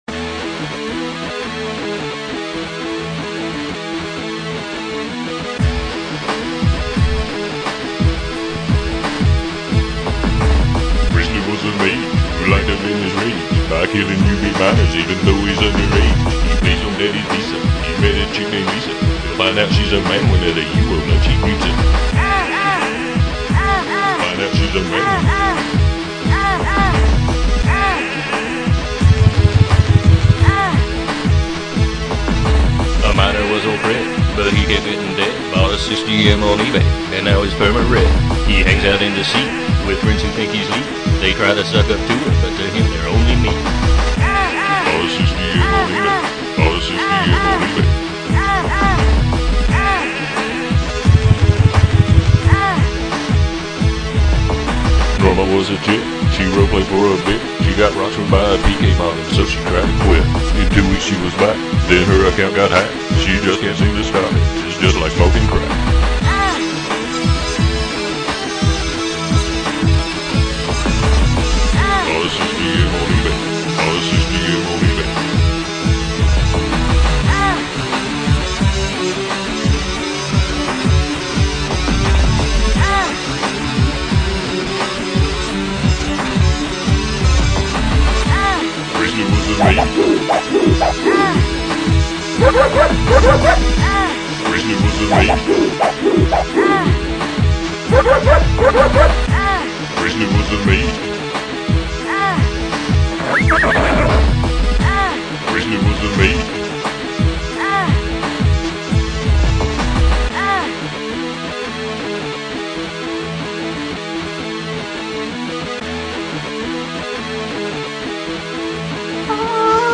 UO Rap!
I ran my amp's line out into my PC's line in, and it sounded pretty good! I had to record the vocals with this crappy little mike that came with a webcam.  It left much to be desired.